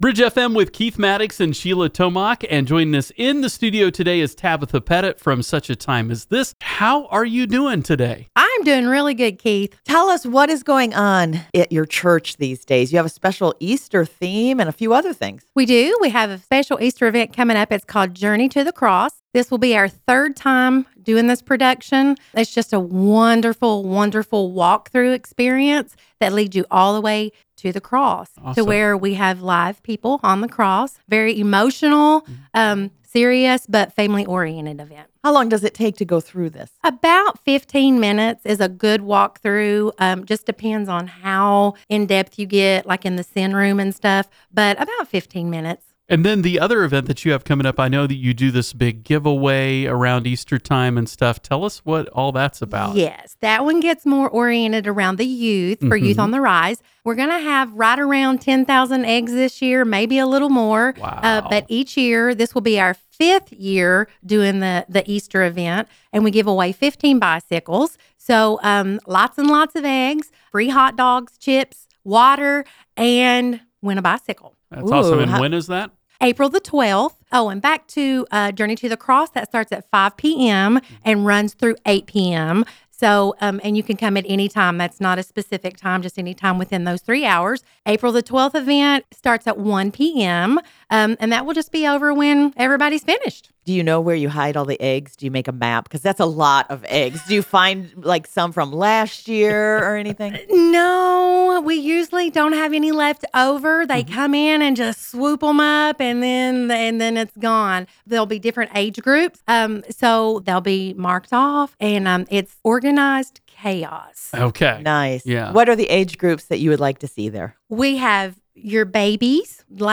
such-a-time-as-this-easter-interview-web.mp3